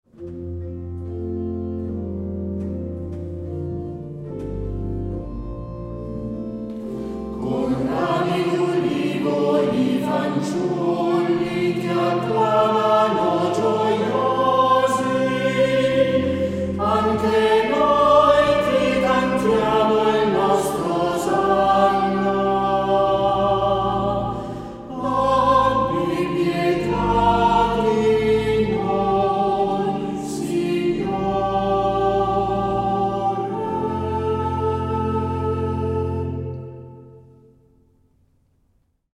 Questa antifona va eseguita nella domenica delle palme nell’unica messa in cui si benedicono gli ulivi e si fa la processione con il popolo.